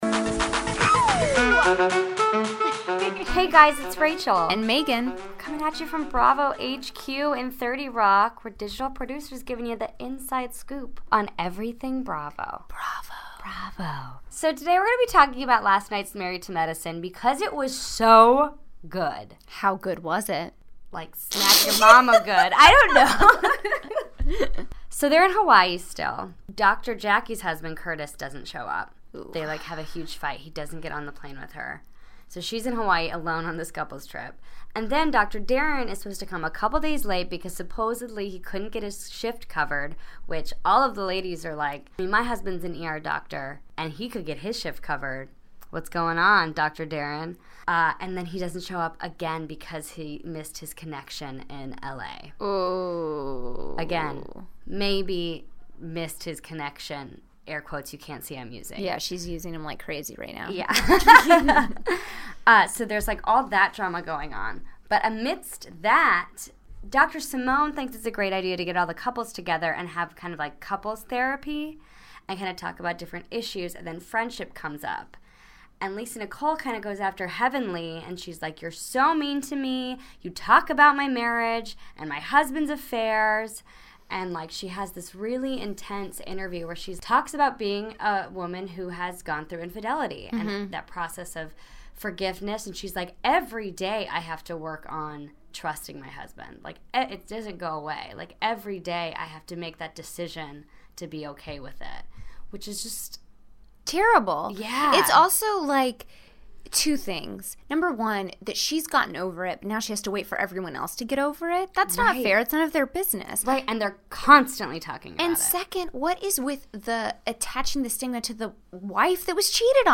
Saturday, January 14, 2017 - From Bravo HQ in New York City, we're breaking down maybe the most amazing episode of Married to Medicine ever. Accusations are flying as the ladies and their husbands sit down in Hawaii to clear the air.